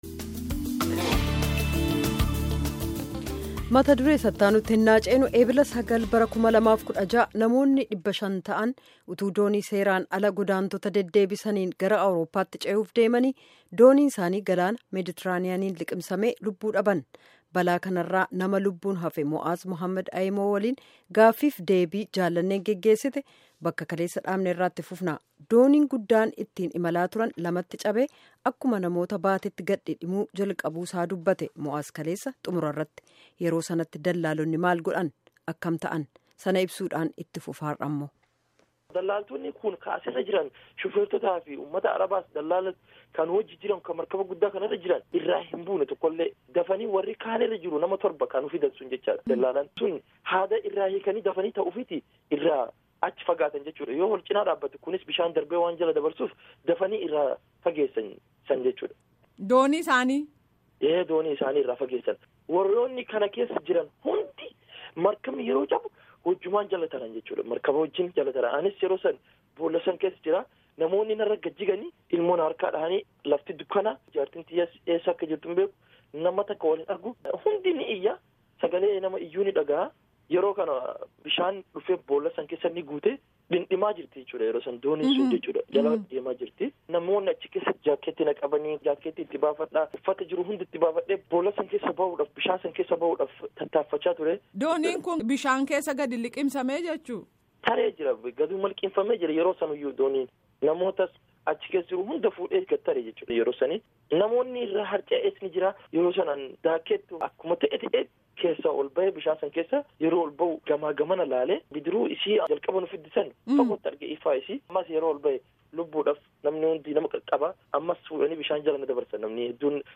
Gaafii fi deebii